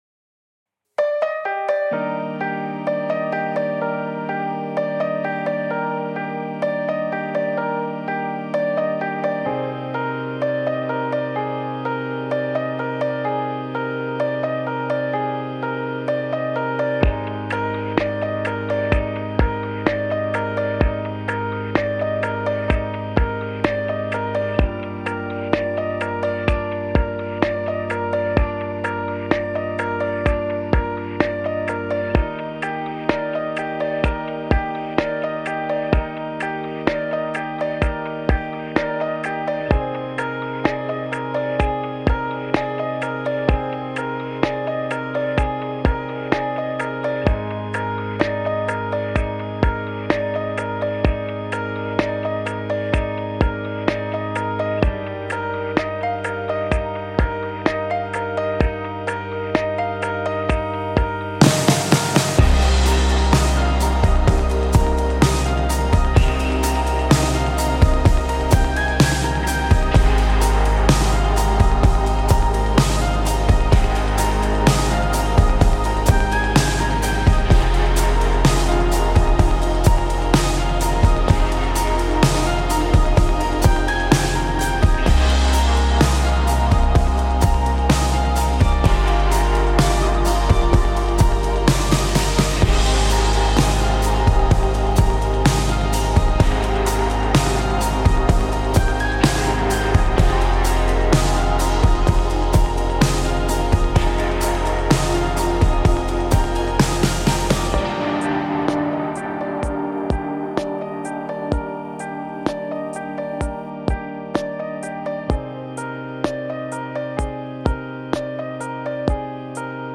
This is the music only [no lyrics] version of the song.